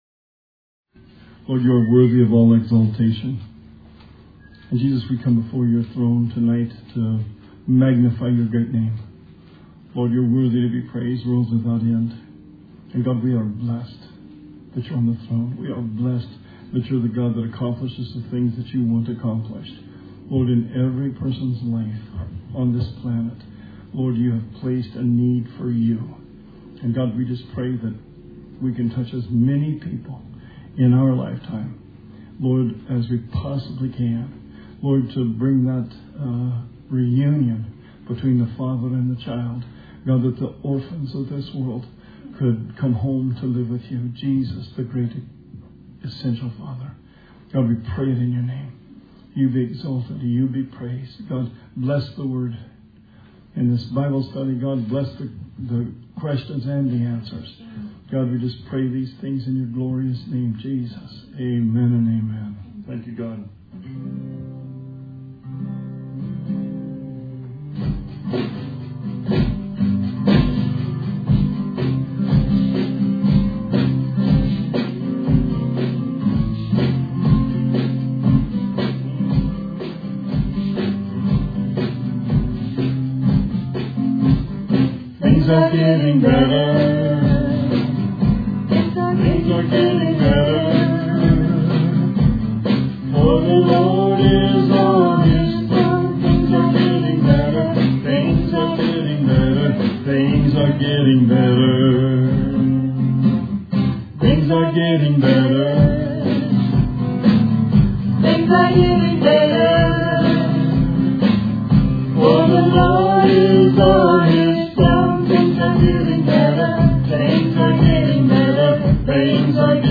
Bible Study 4/8/20